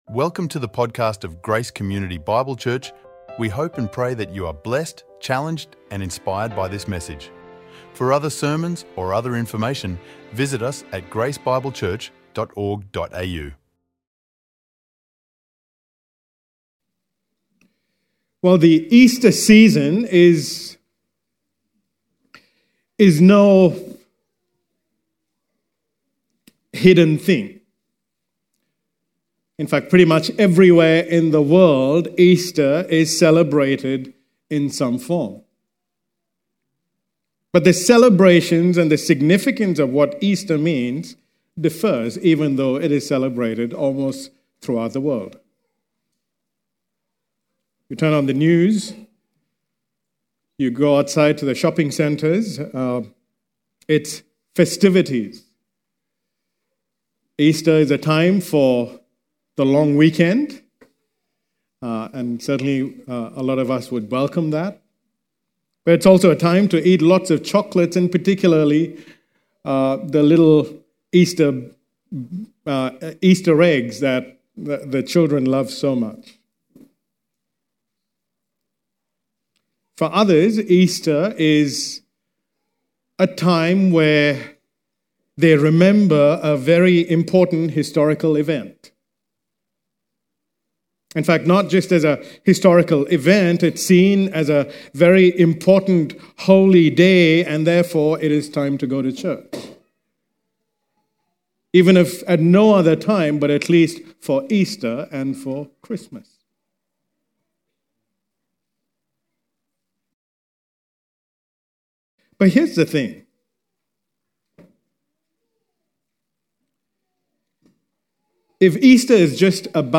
recorded live at Grace Community Bible Church